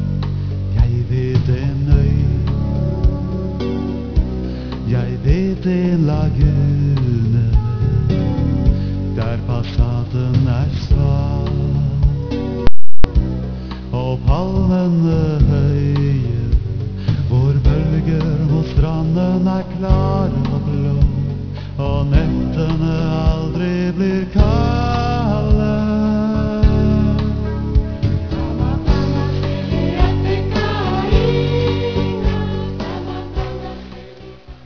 Jeg beklager lydkvaliteten, men det er altså audio -"levende lyd" - som er omgjort til korte 8-bits wave-filer for at de ikke skal ta "for ever" å laste ned.
Framført live på Seinsveip vinter 1995.